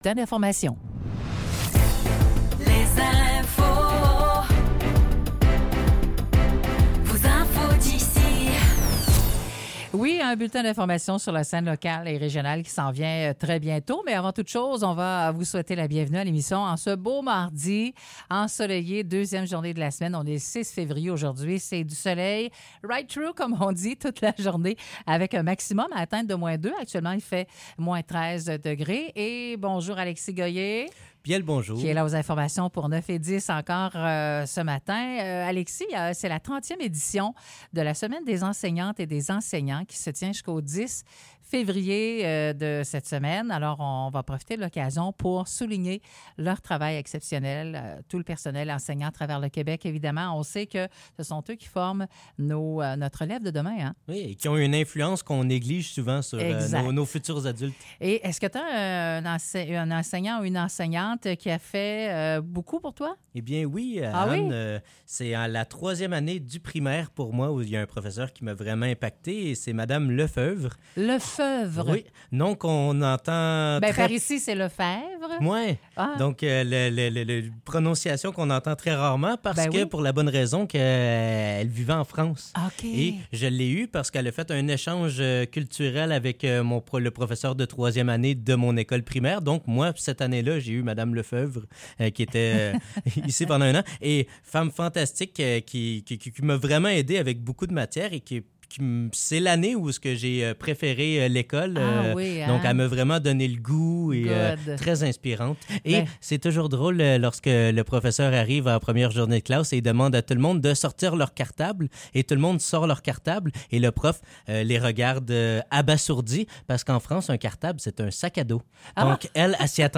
Nouvelles locales - 6 février 2024 - 9 h